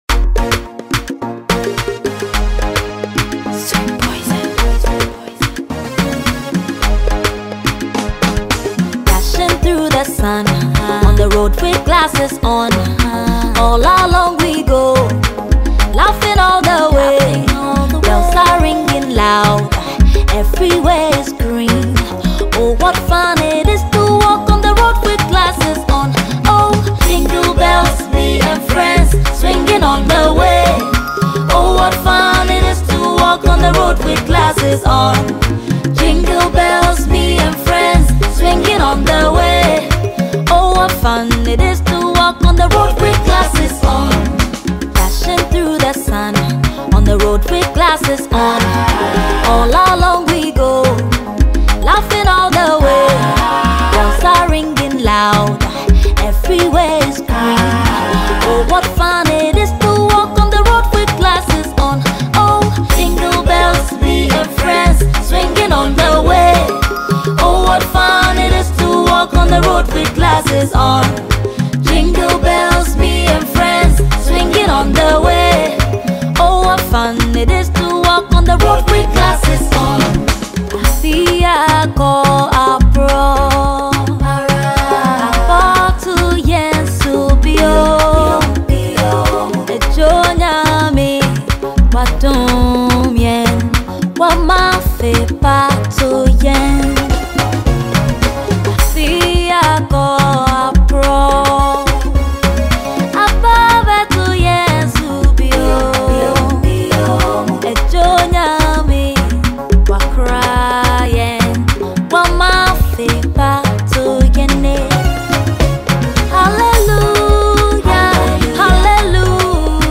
female vocalist
official Christmas song